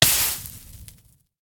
Minecraft Version Minecraft Version latest Latest Release | Latest Snapshot latest / assets / minecraft / sounds / entity / player / hurt / fire_hurt2.ogg Compare With Compare With Latest Release | Latest Snapshot
fire_hurt2.ogg